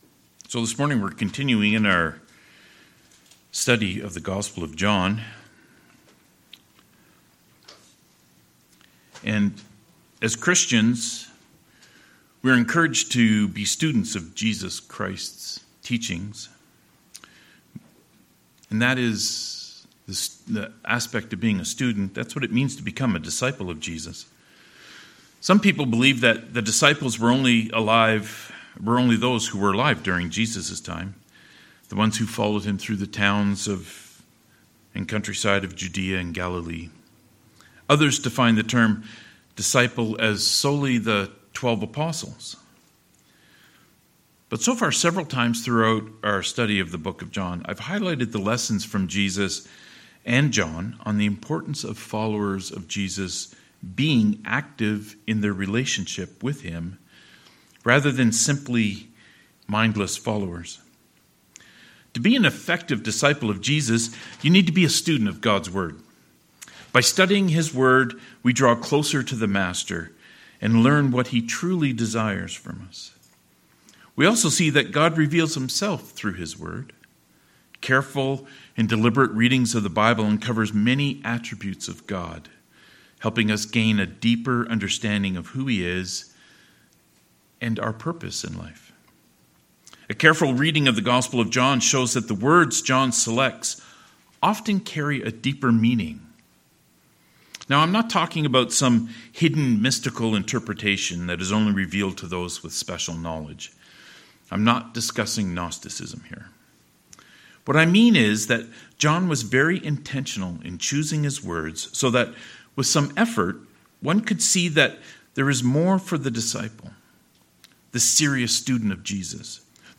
20-36 Service Type: Sermons « What Are You Willing to Sacrifice for Jesus?